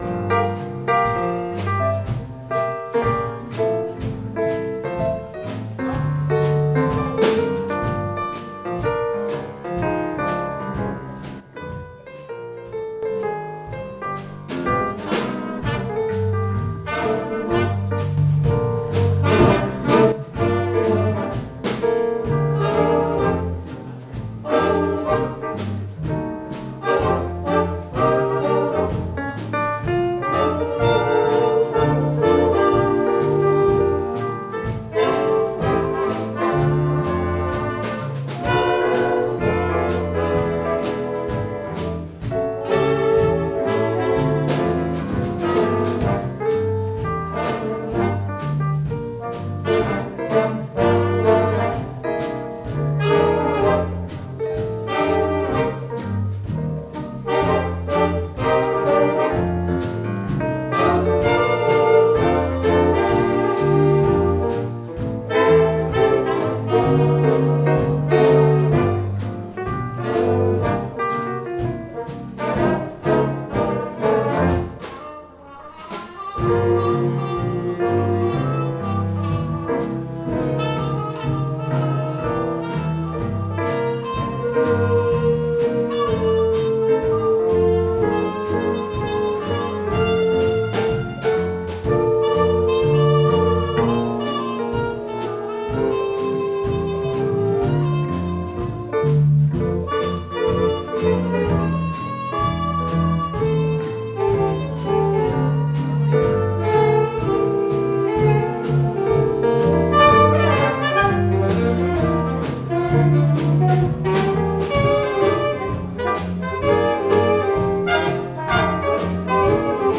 Big Band storband bröllop fest event party jazz